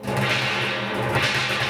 BON ROLL2A.wav